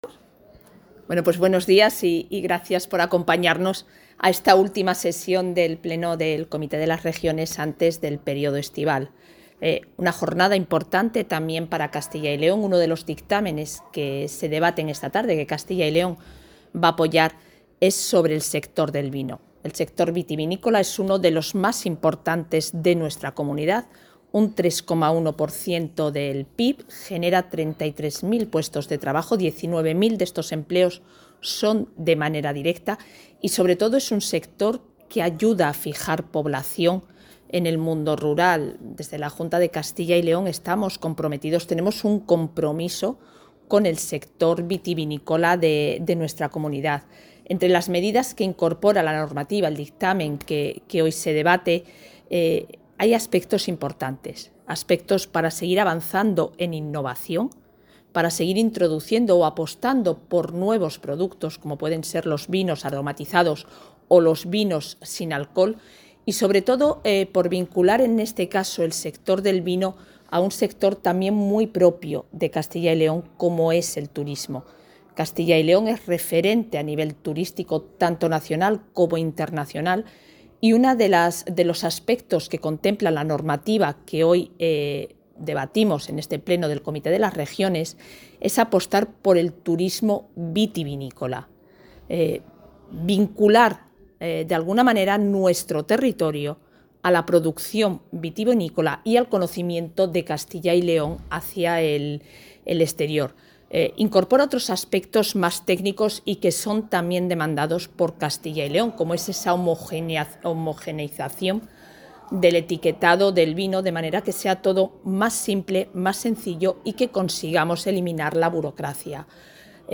Declaraciones de la vicepresidenta.
Isabel Blanco ha intervenido en el pleno del Comité de las Regiones de la Unión Europea, donde ha propuesto llevar a todos los países socios las políticas de la Junta de apoyo al relevo generacional y ha defendido la necesidad de desarrollar el futuro reglamento de la Comisión de manera consensuada con regiones y entes locales.